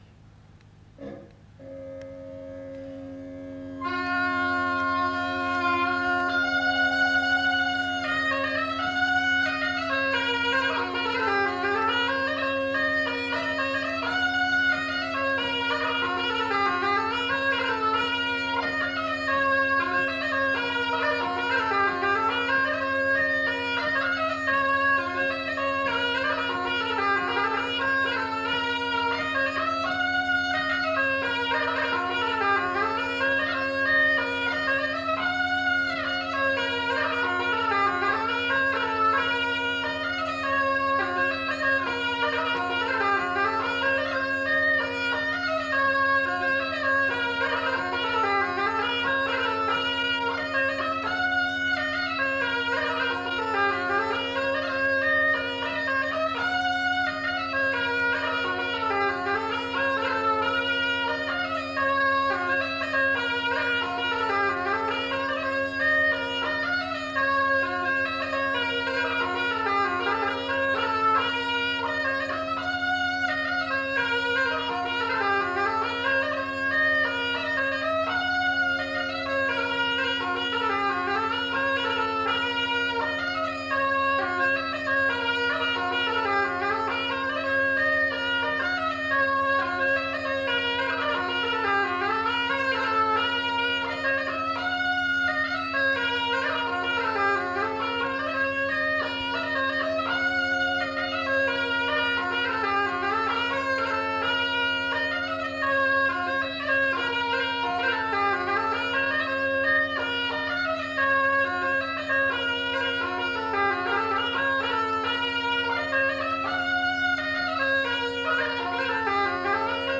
Aire culturelle : Cabardès
Genre : morceau instrumental
Instrument de musique : craba
Danse : scottish